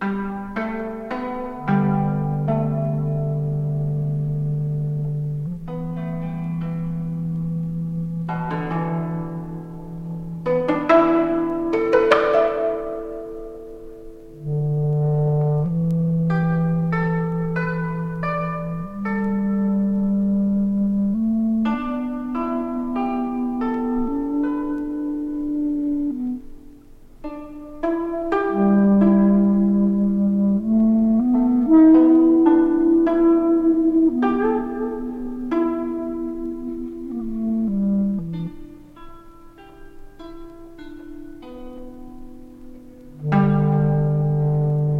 三者三様の日本的な美しい音色と響きがどこかへ誘ってくれる。
Jazz, New Age, Abstract　Germany　12inchレコード　33rpm　Stereo